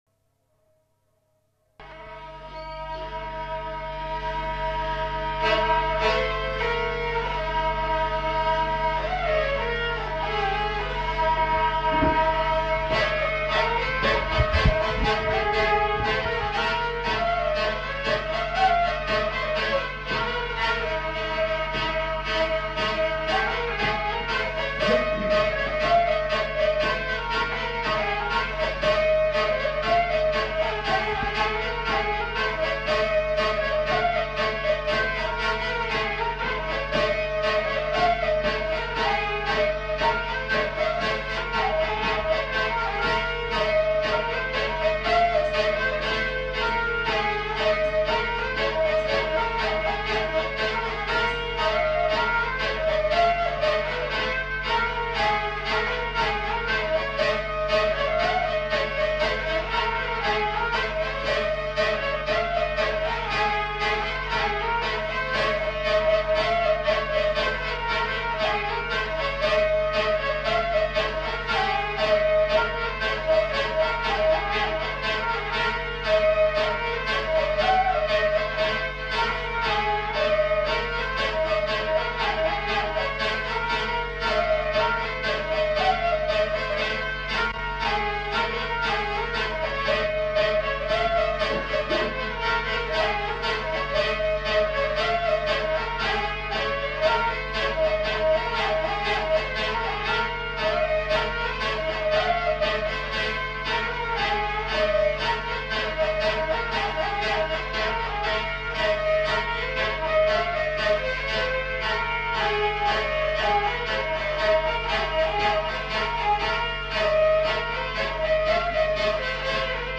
Aire culturelle : Gabardan
Lieu : Mauléon-d'Armagnac
Genre : morceau instrumental
Instrument de musique : vielle à roue
Danse : rondeau
Notes consultables : Enchaînement de deux thèmes.